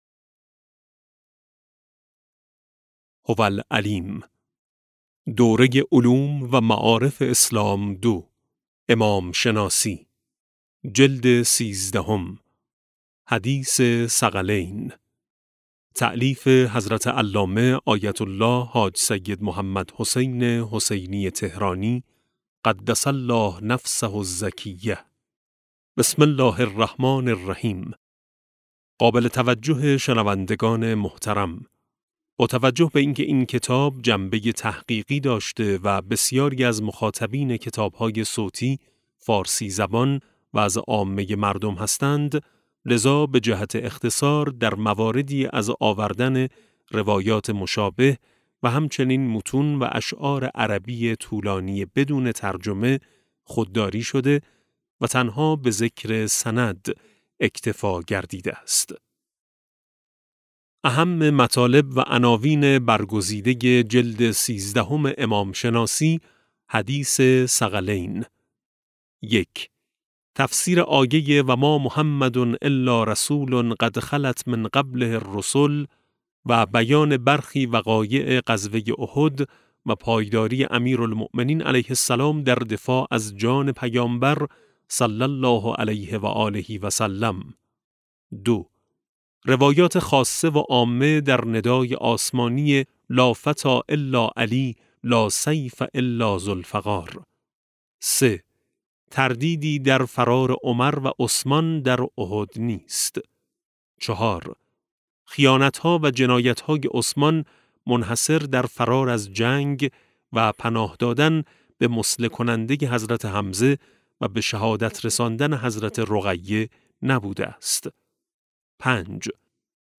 کتاب صوتی امام شناسی ج 13 - جلسه1